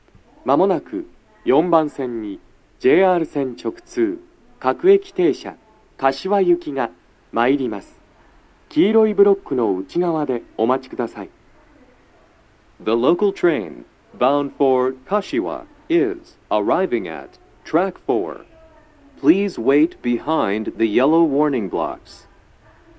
鳴動中に入線してくる場合もあります。
男声
接近放送2